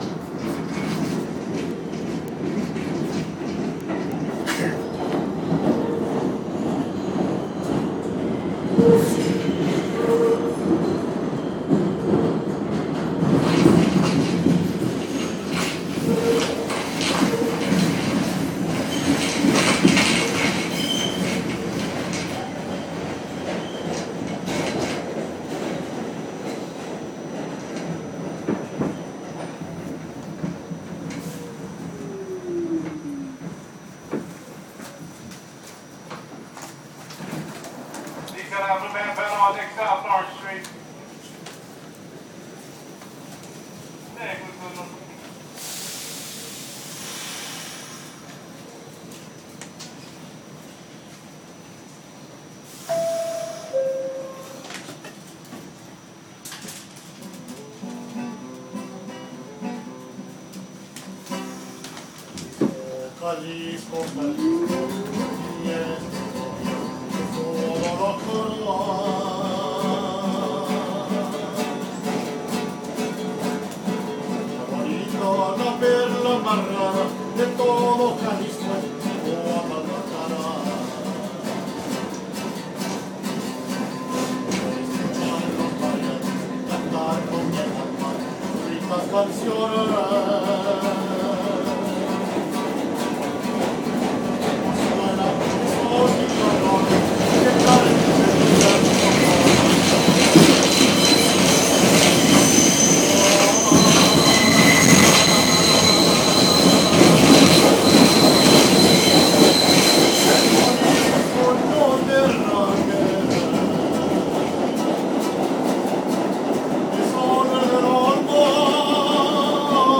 Music on the subway.